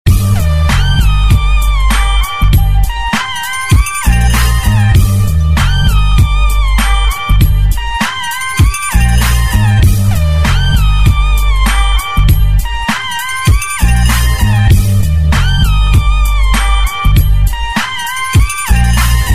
• Качество: 128, Stereo
заставка игры